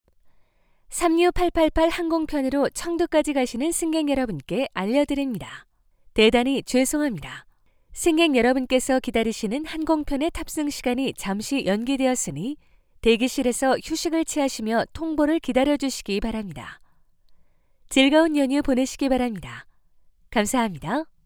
韩语样音试听下载
Kr-female-DK005-demo.mp3